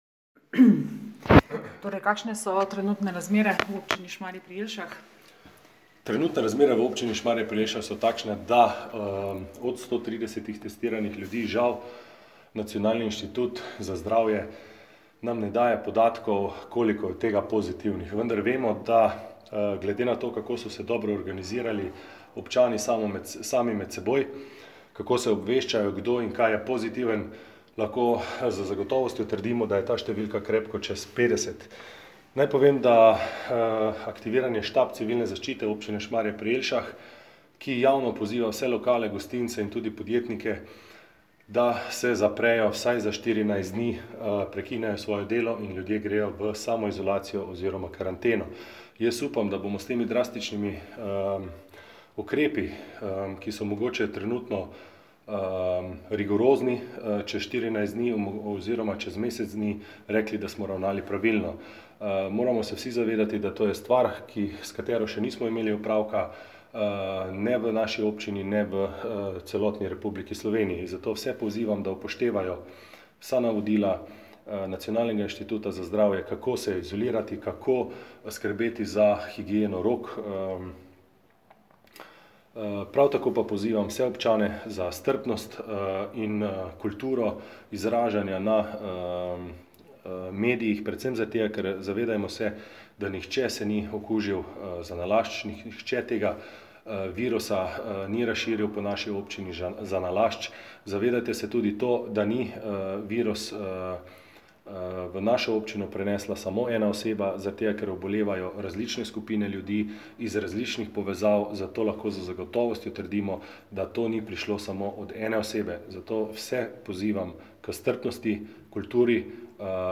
Šmarski župan Matija Čakš je o razmerah glede okuženih z novim koronavirusom v Občini Šmarje pri Jelšah novinarjem med drugim povedal, da naj bi simptome, ki kažejo na okužbo z novim koronavirusom, imelo najmanj 50 občanov Šmarja. Poziva k strpnosti.